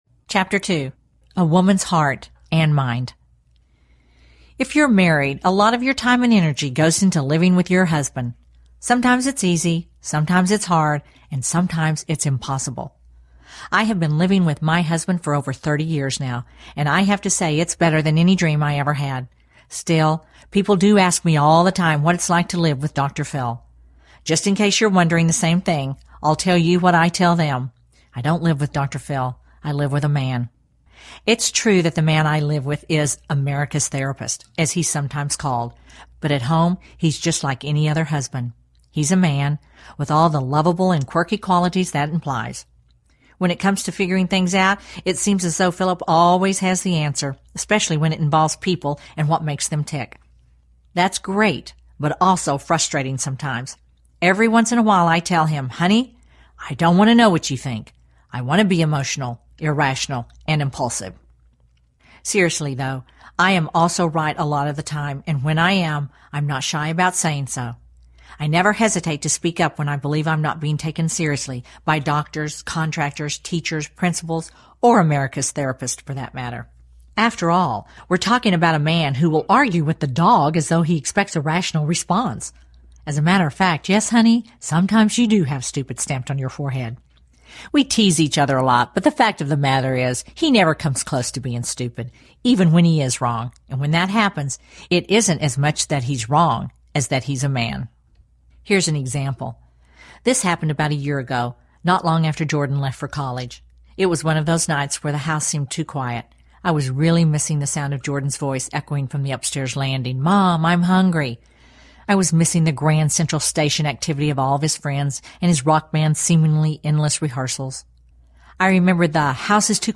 Inside My Heart Audiobook
Narrator
5.2 Hrs. – Unabridged